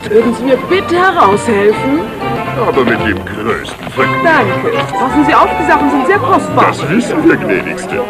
Ich hätte da mal eine Münchner Trick-Synchro von 1989 mit den üblichen Verdächtigen.
Frau des Bürgermeisters